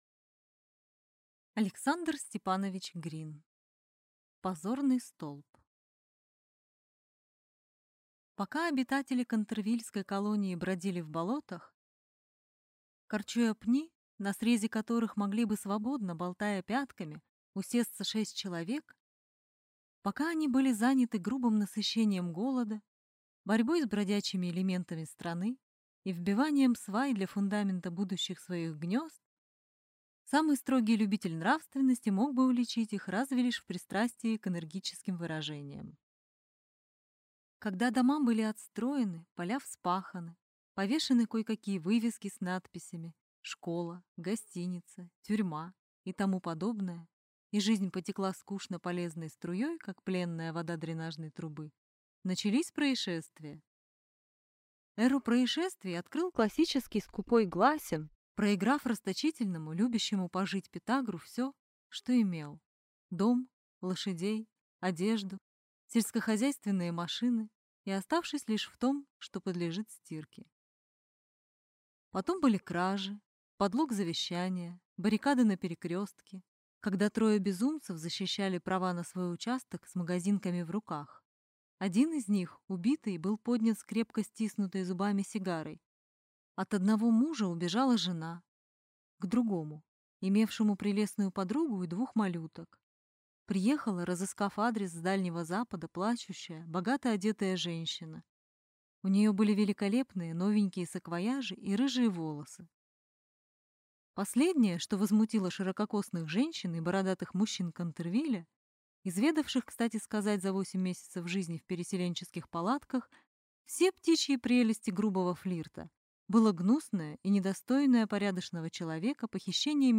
Аудиокнига Позорный столб | Библиотека аудиокниг